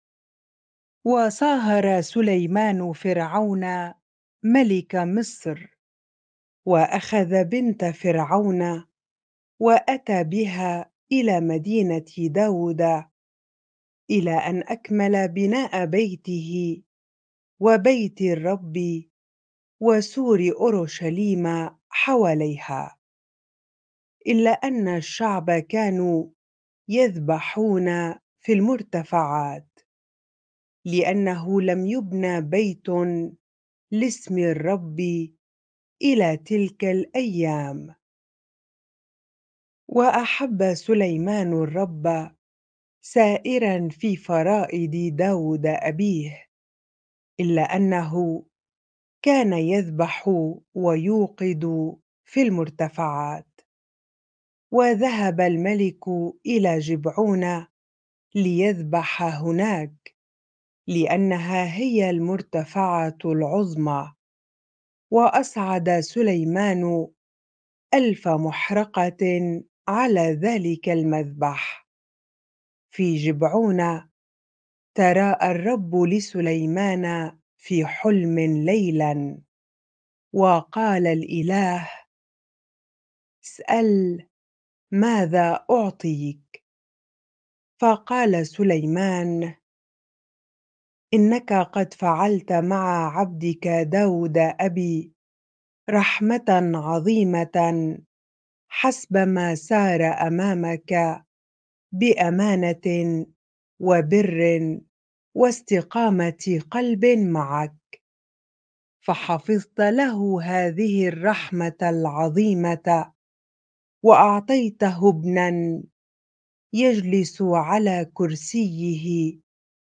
bible-reading-1 Kings 3 ar